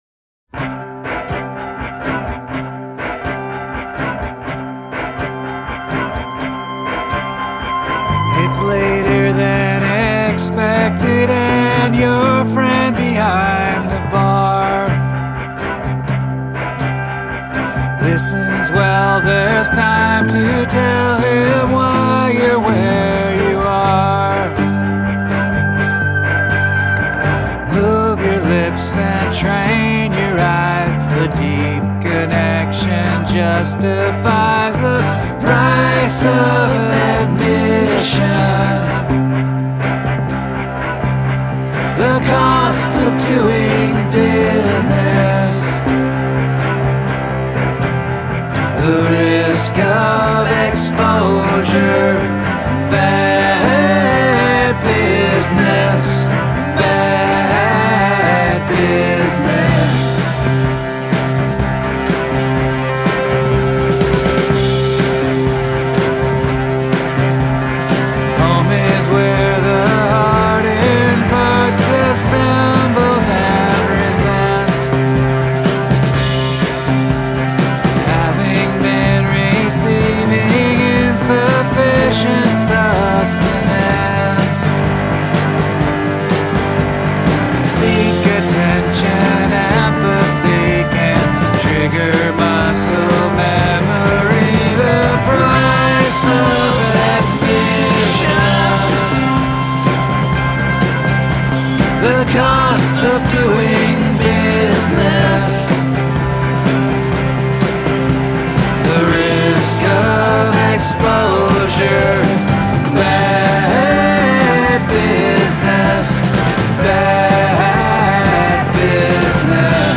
industrial-sounding loop, drummer
super-aggressive, all-over-the-place keyboard approach
And, finally, what about that crazy jam at the end?